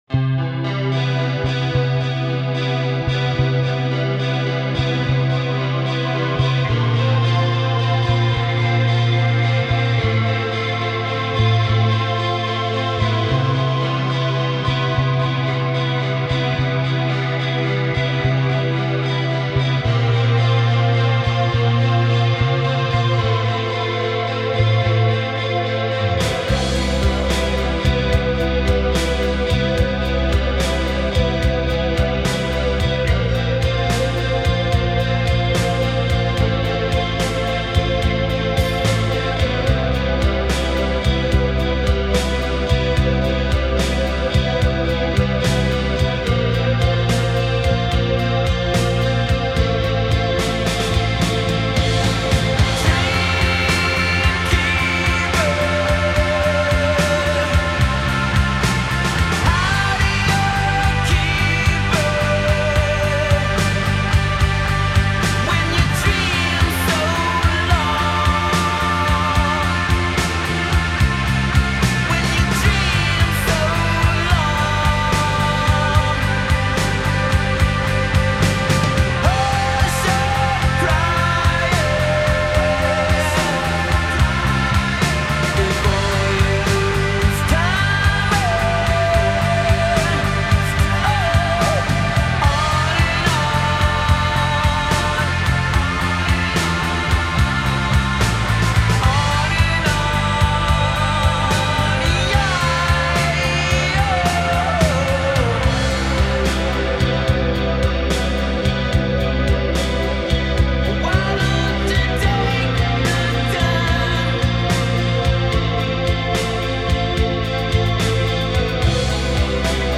Cornerstones of Shoegaze
rich musical palette conveys a stunning aural presence